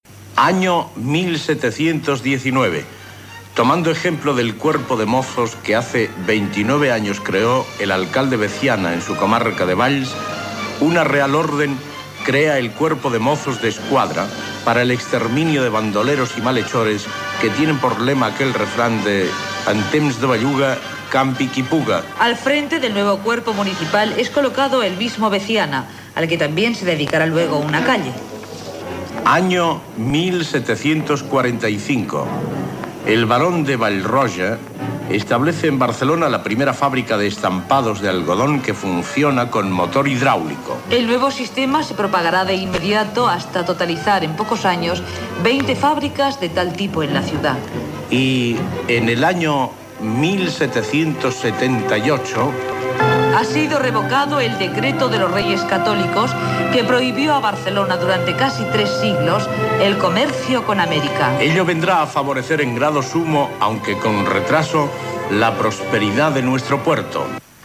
Presentador/a